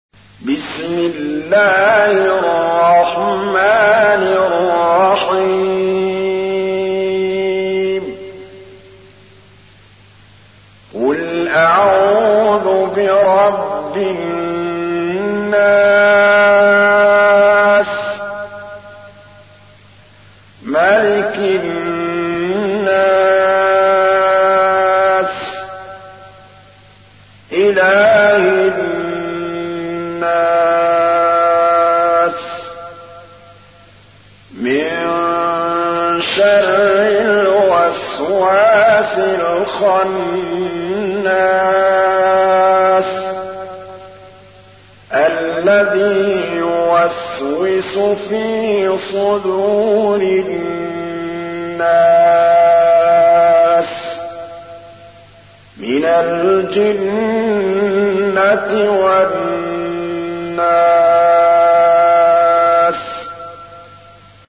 تحميل : 114. سورة الناس / القارئ محمود الطبلاوي / القرآن الكريم / موقع يا حسين